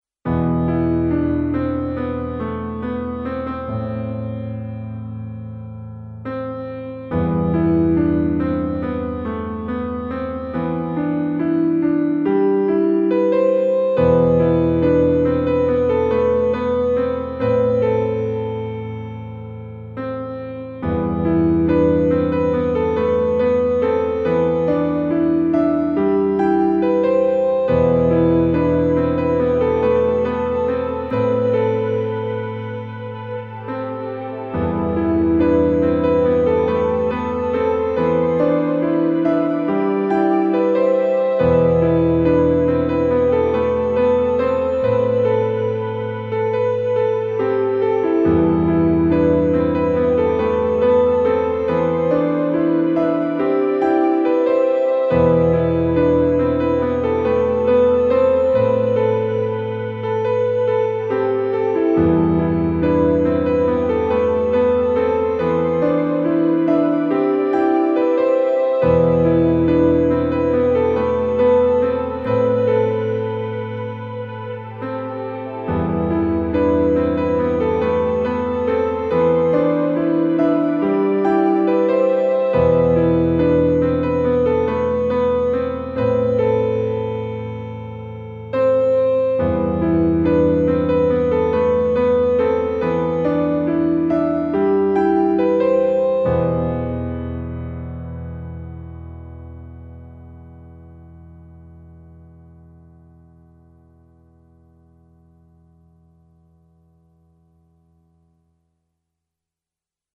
Piano + Mellotron